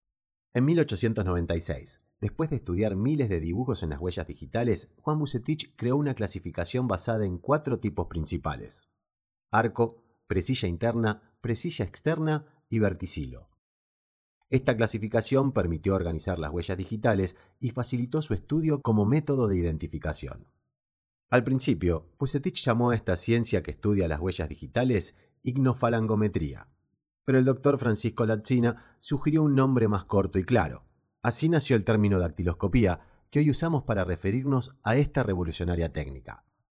Audiolibros